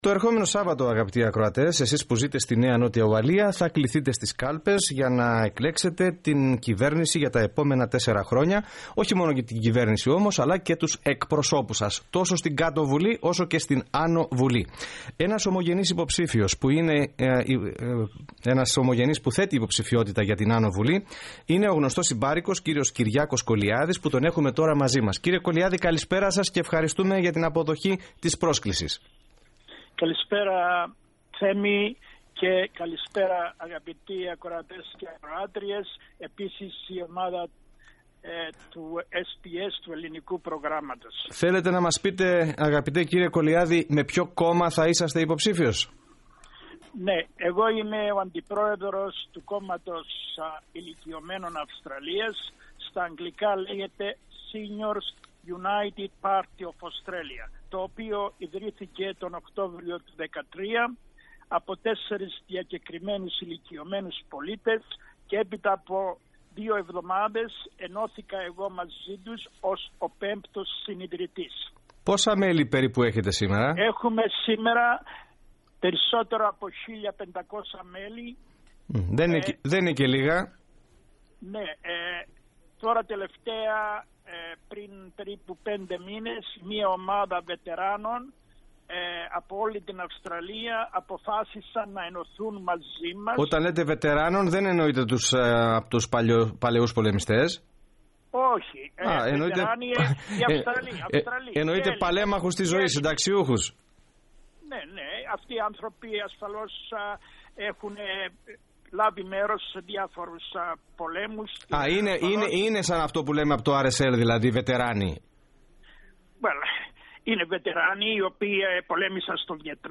The interview is in Greek.